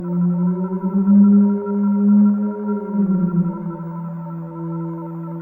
Theremin_Atmos_02.wav